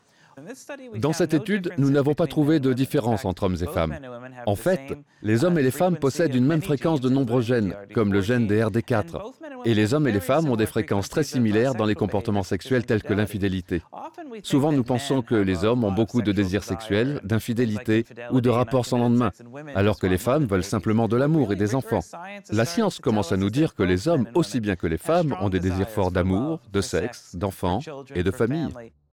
Documentaire
Voix off
- Baryton-basse